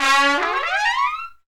Index of /90_sSampleCDs/Roland LCDP06 Brass Sections/BRS_Tpts FX menu/BRS_Tps FX menu